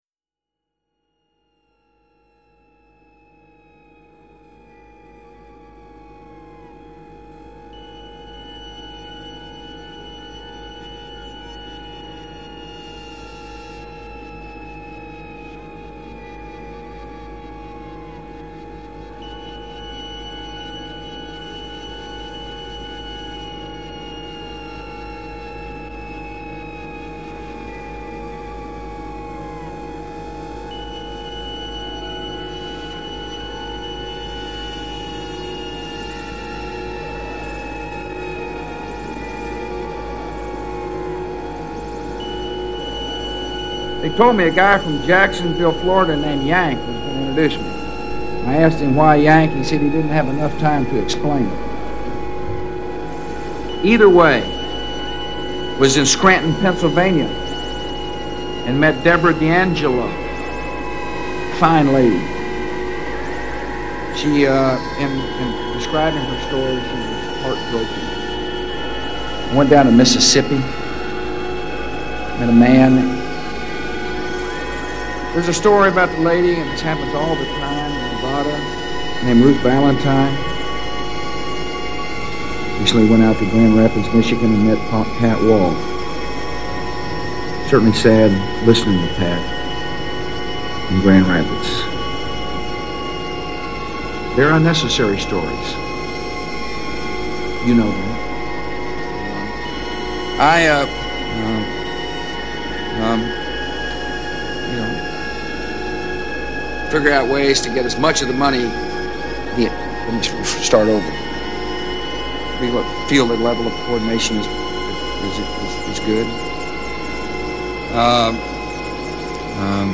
heres three pieces of cut up bush gibbering put to music.